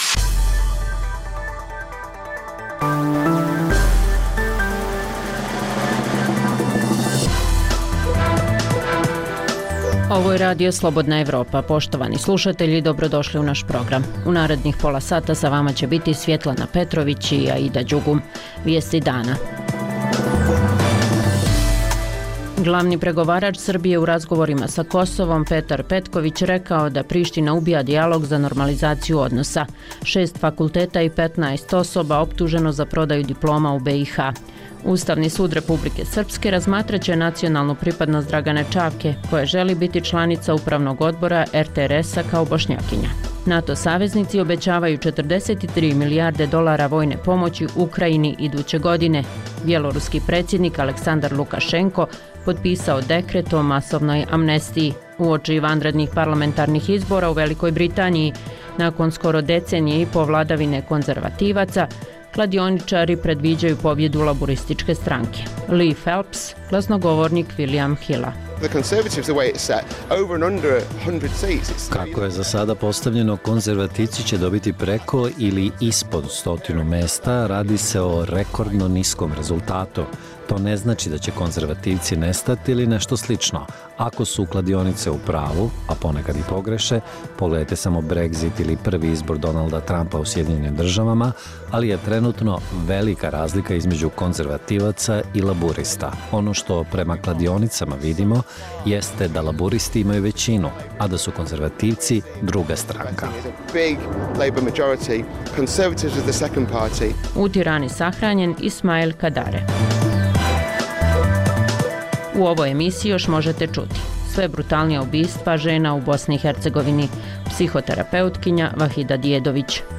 Poslušajte Aktuelno, regionalni radijski program
Dnevna informativna emisija Radija Slobodna Evropa o događajima u regionu i u svijetu. Vijesti, teme, analize i komentari.